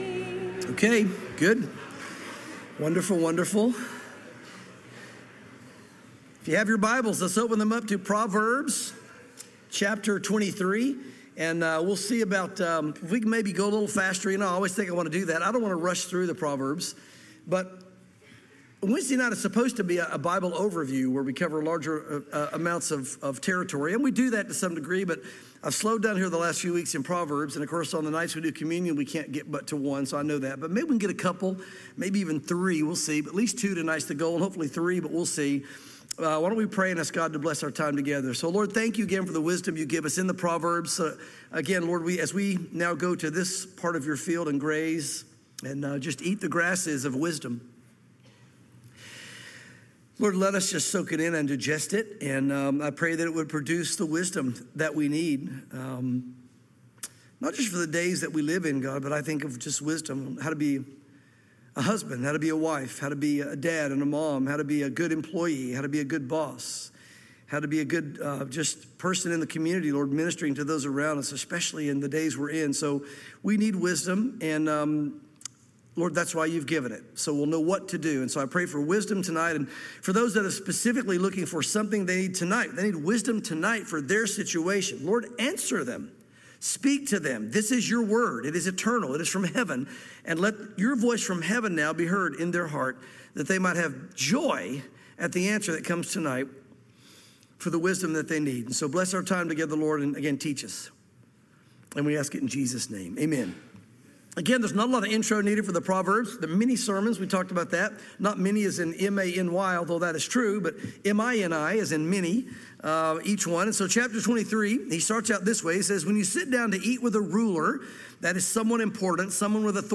sermons
Calvary Chapel Knoxville